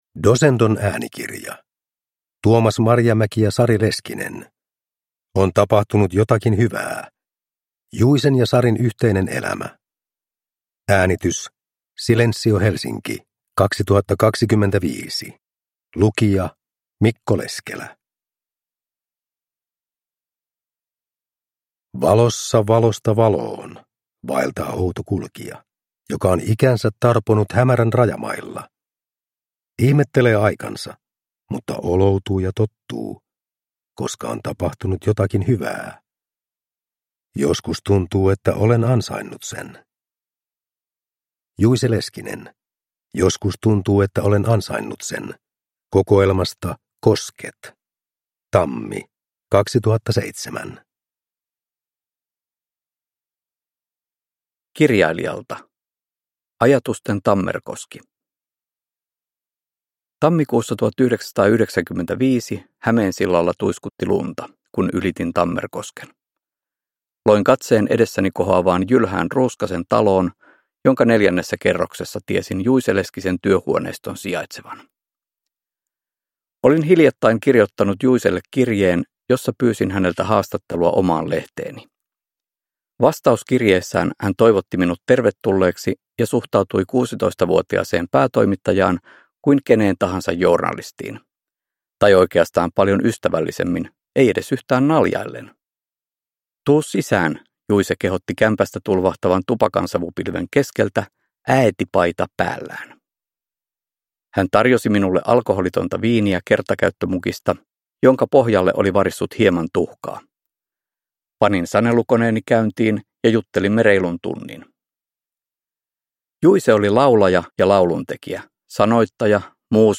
On tapahtunut jotakin hyvää (ljudbok) av Tuomas Marjamäki